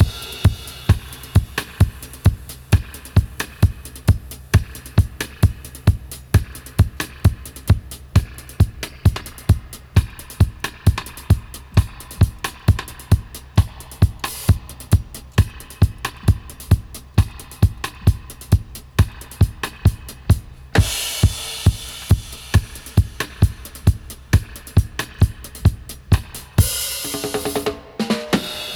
134-DUB-04.wav